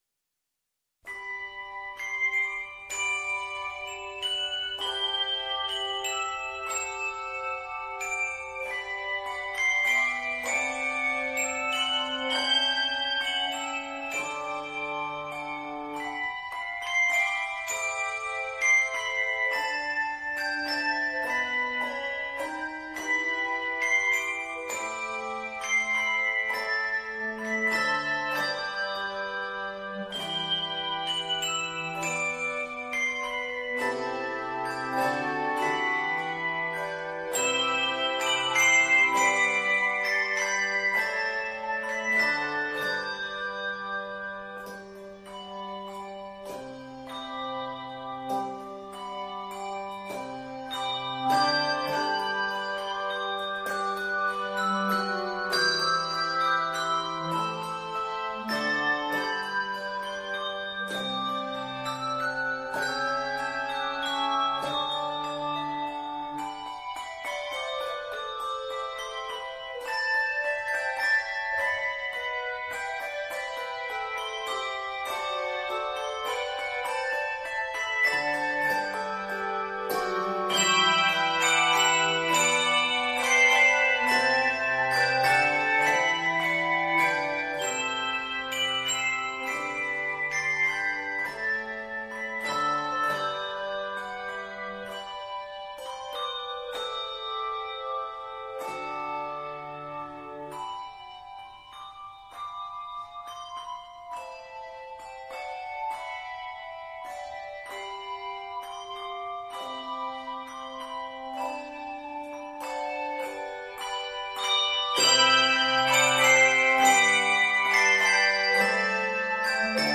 handbells
lovely and worshipful